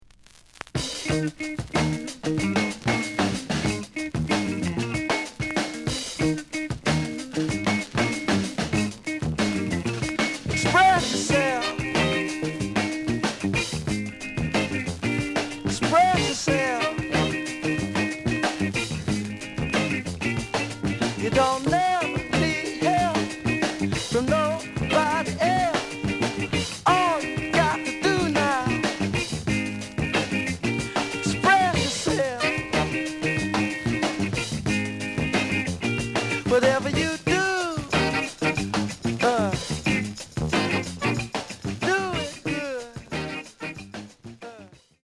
The audio sample is recorded from the actual item.
●Genre: Funk, 70's Funk
Some noise on A side.